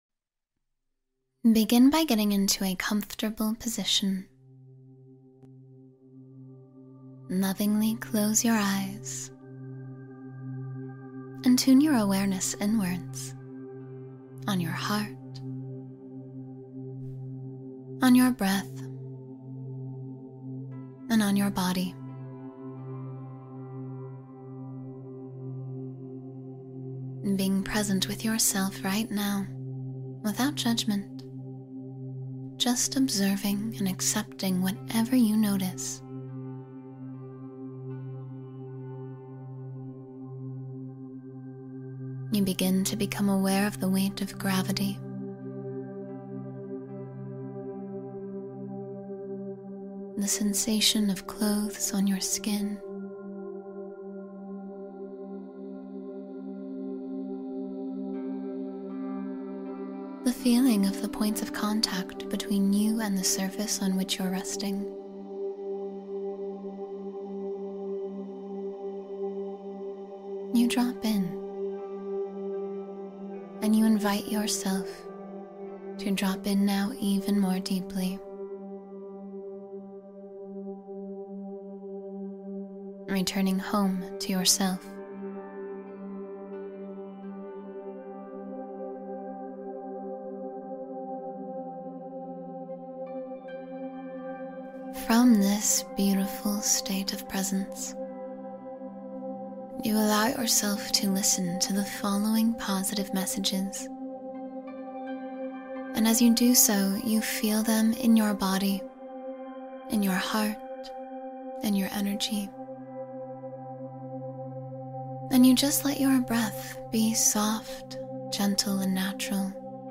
Receive a Positive Message — Guided Meditation for Your Best Day